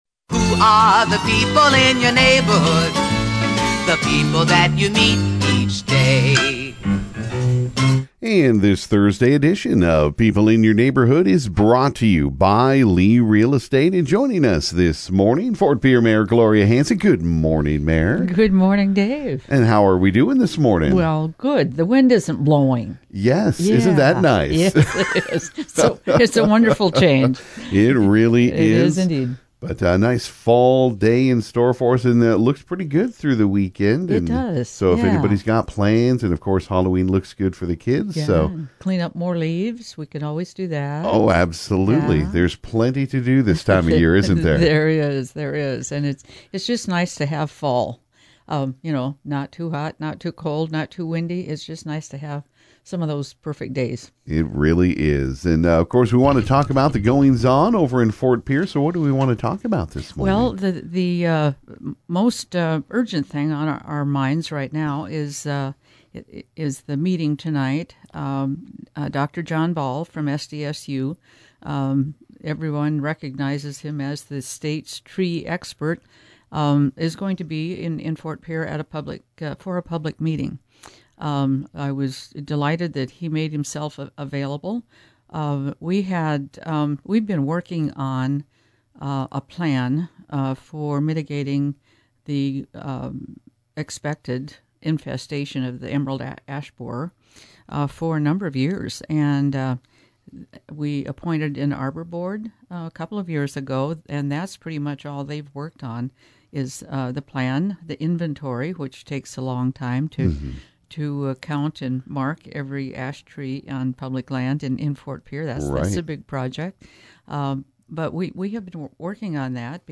This morning Ft. Pierre Mayor Gloria Hanson stopped into the KGFX studio this morning.